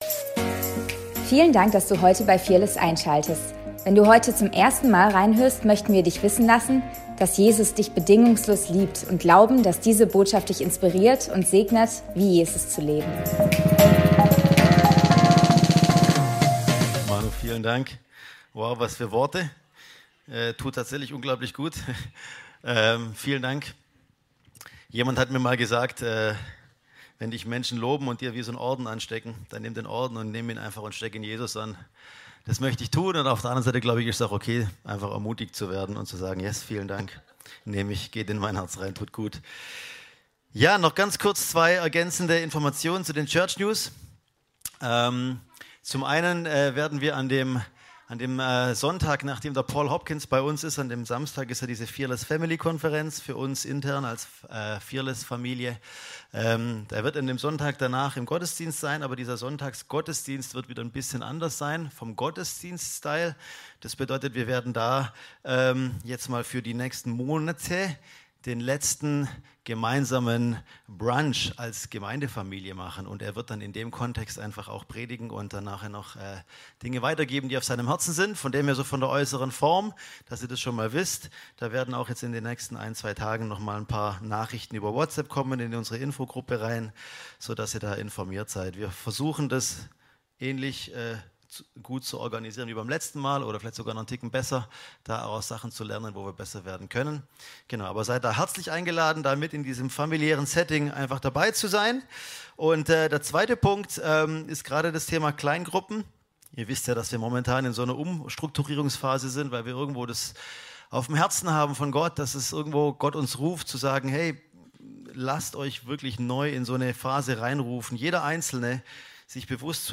Predigt vom 16.03.2025